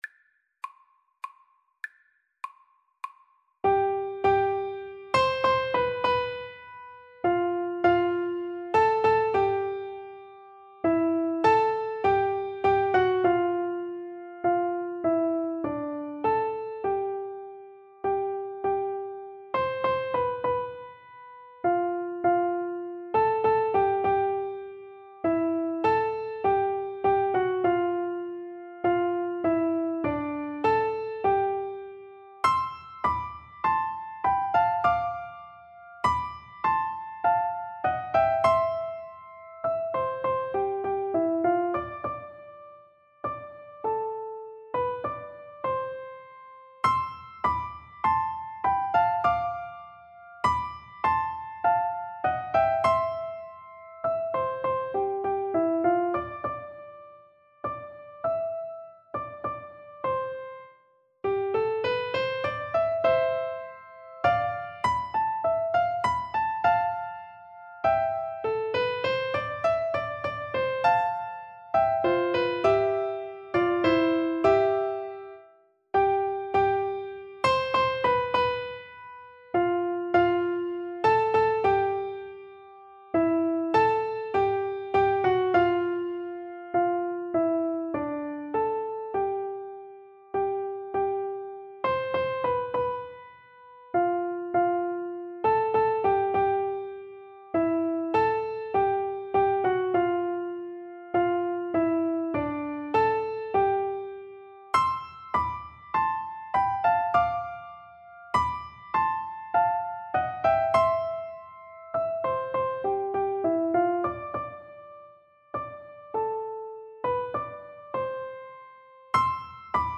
C major (Sounding Pitch) (View more C major Music for Piano Duet )
Andantino (View more music marked Andantino)